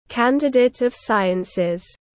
Phó tiến sĩ tiếng anh gọi là candidate of sciences, phiên âm tiếng anh đọc là /ˈkæn.dɪ.dət əv ˈsaɪ.əns/.
Candidate of sciences /ˈkæn.dɪ.dət əv ˈsaɪ.əns/